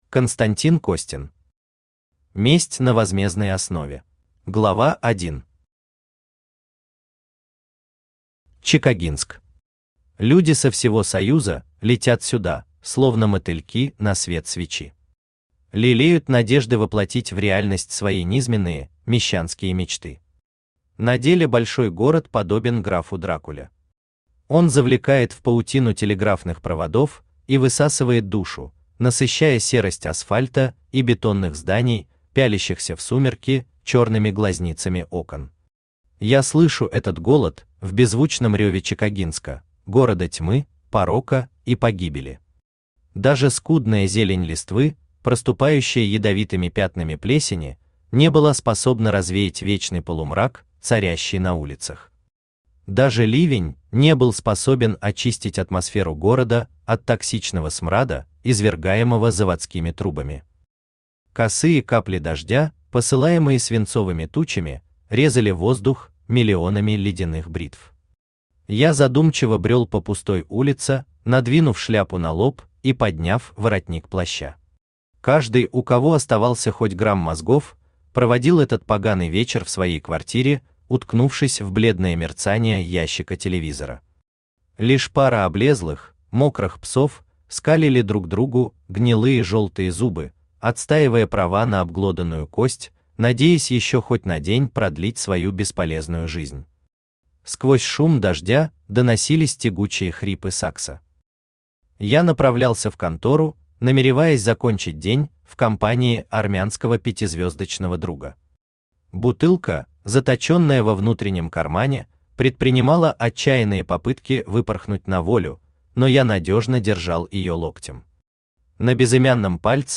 Aудиокнига Месть на возмездной основе Автор Константин Александрович Костин Читает аудиокнигу Авточтец ЛитРес.